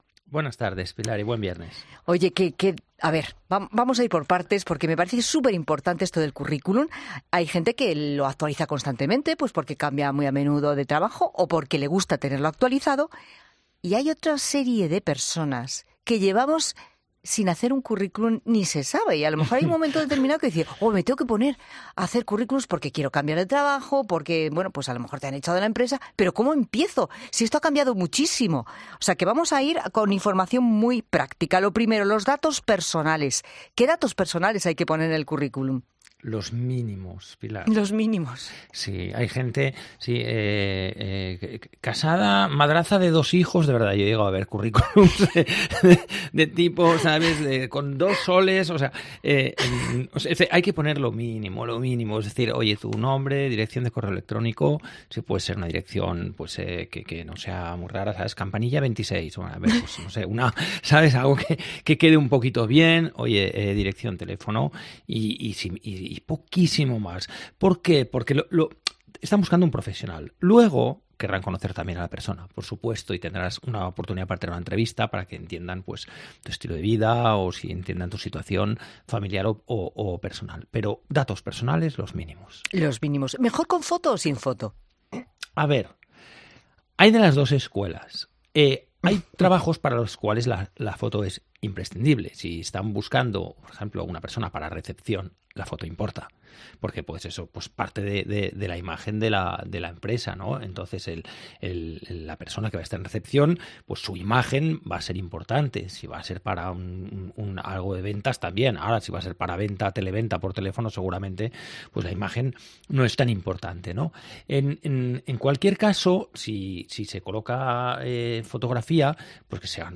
ha charlado sobre este tema con el colaborador y economista, Fernando Trias de Bes